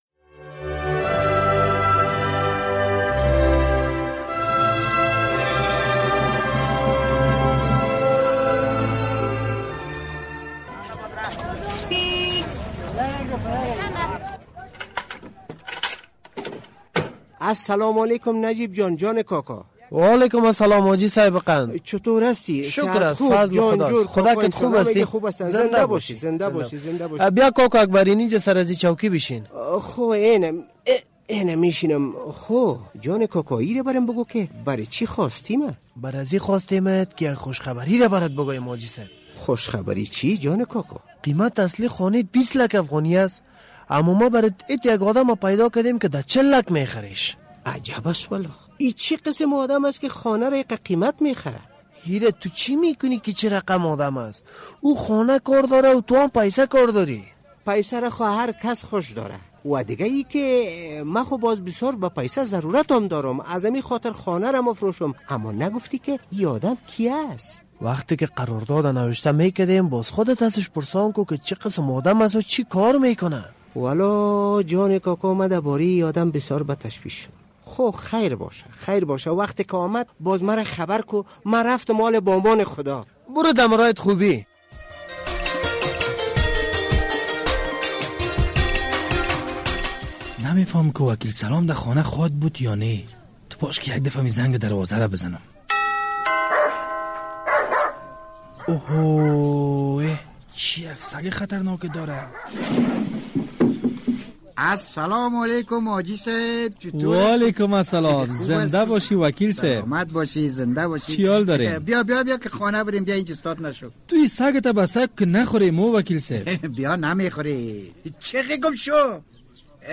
این را در درامه می شنویم